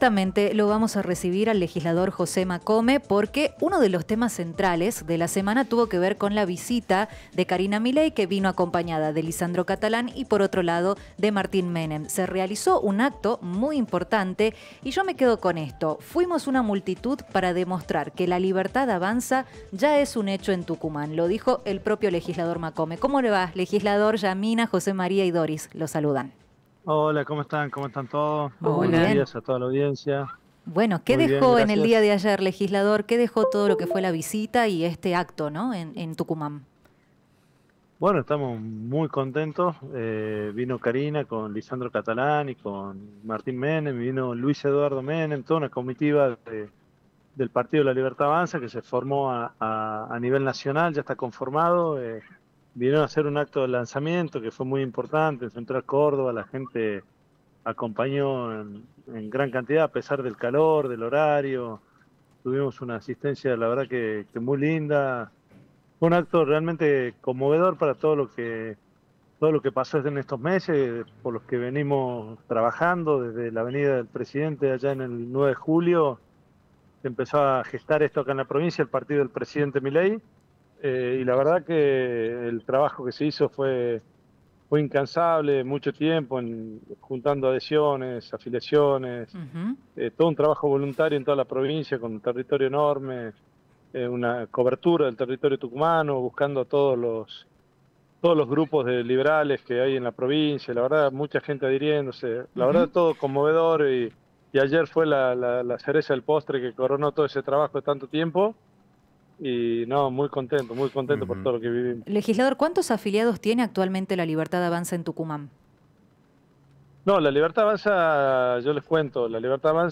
El legislador provincial de La Libertad Avanza, José Macome, en diálogo con Café Prensa se refirió al lanzamiento del espacio a nivel nacional en la provincia.